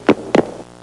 Hanging Up Phone Sound Effect
Download a high-quality hanging up phone sound effect.
hanging-up-phone.mp3